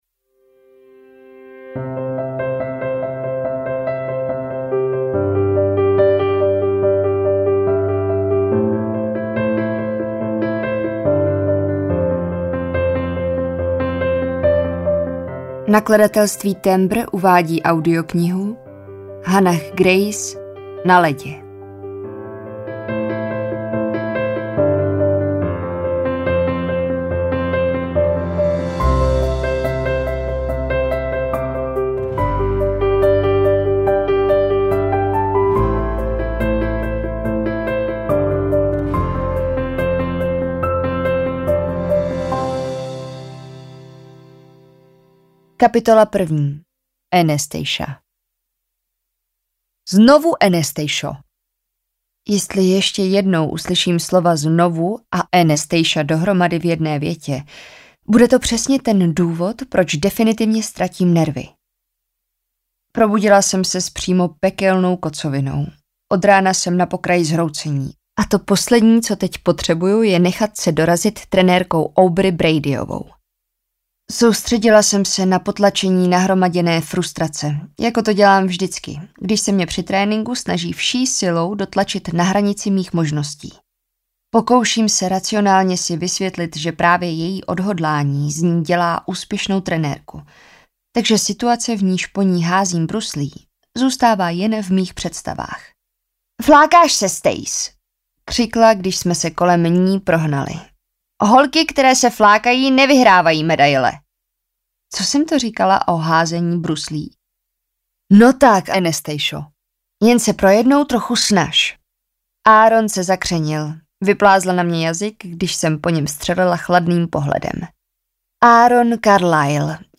Na ledě audiokniha
Ukázka z knihy